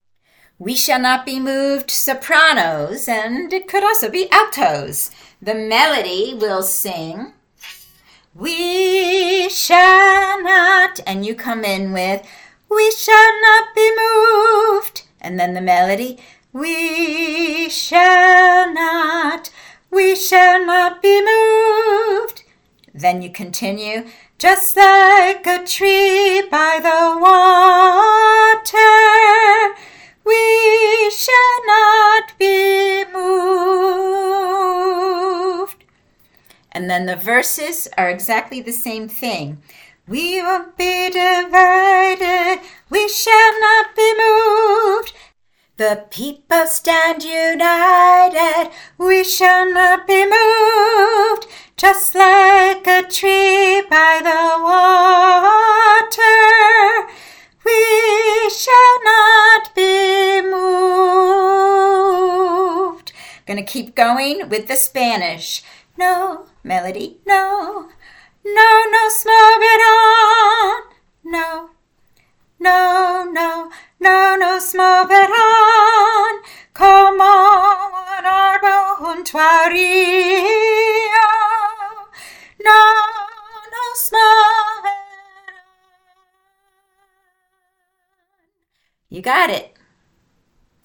We Shall Not Be Moved sop.mp3